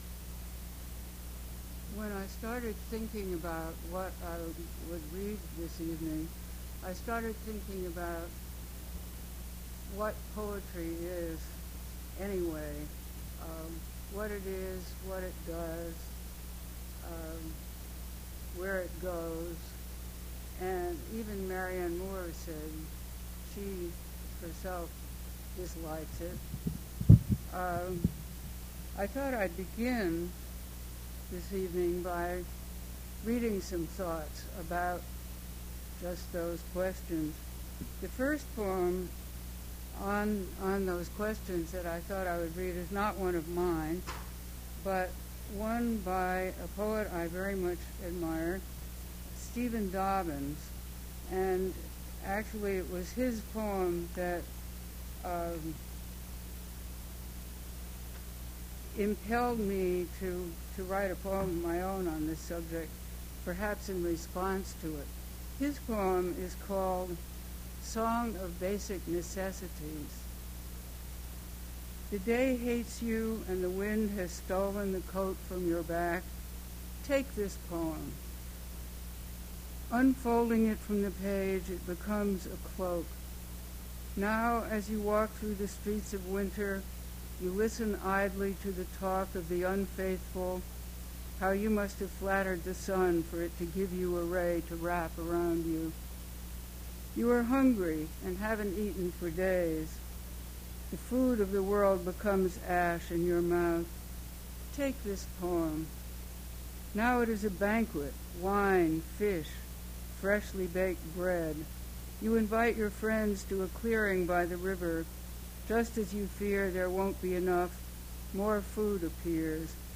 Poetry reading
poetry reading at Duff's Restaurant.
sourced from preservation WAV file that was generated from original audio cassette.
Questionable audio quality - background noise